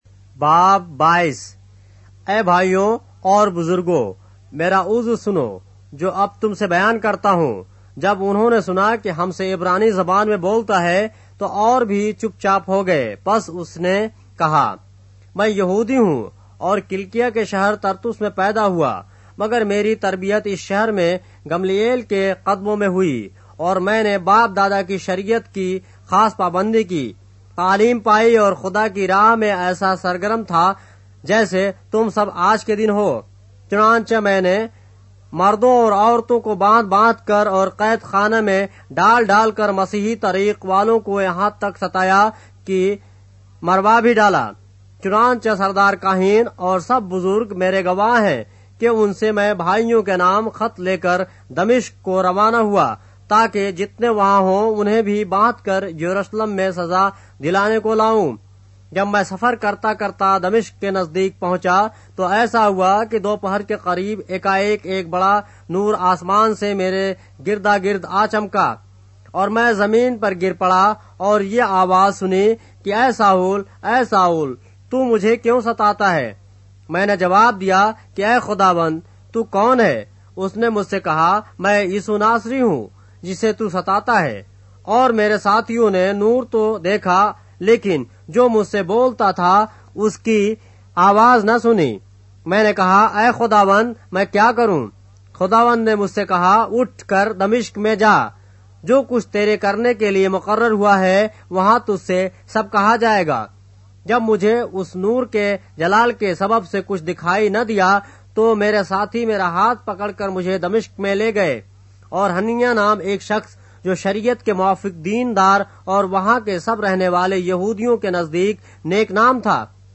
اردو بائبل کے باب - آڈیو روایت کے ساتھ - Acts, chapter 22 of the Holy Bible in Urdu